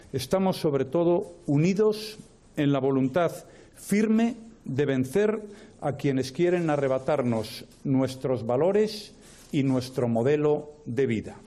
El presidente del Gobierno ha apelado a la unidad constitucional en una declaración institucional en la Delegación del Gobierno en Cataluña.